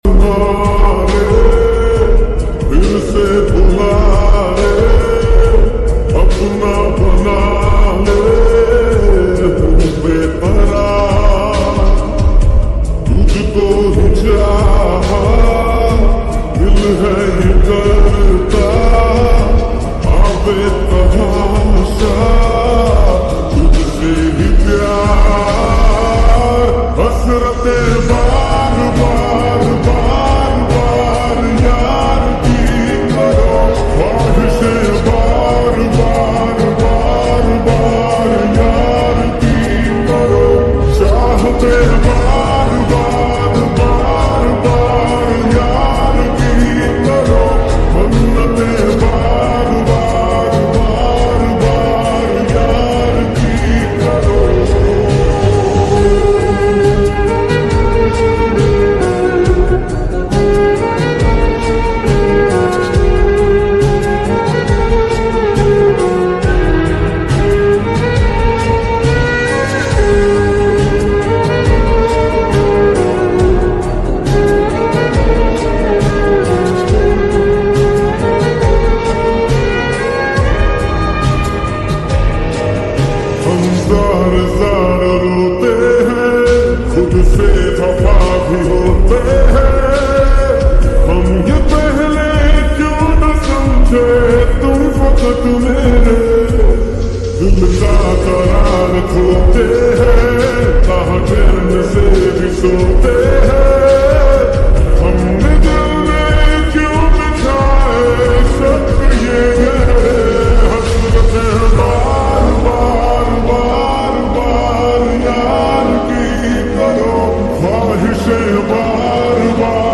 slow and reverd